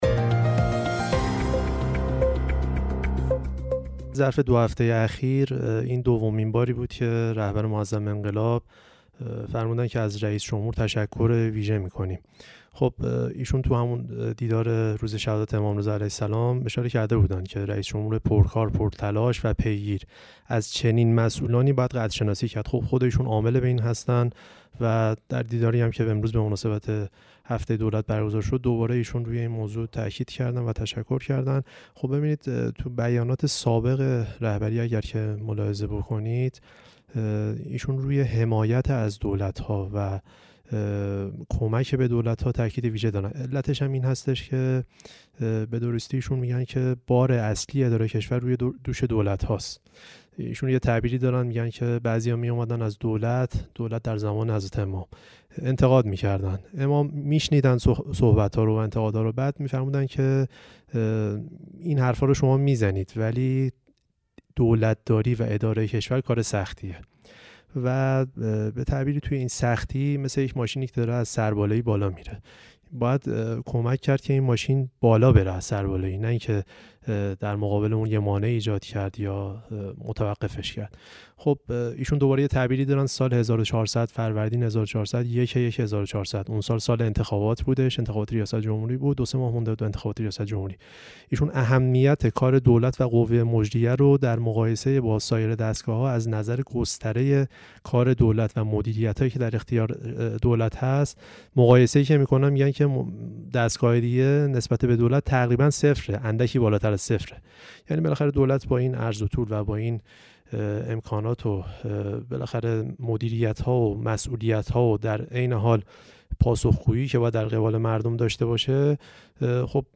کارشناس مسائل سیاسی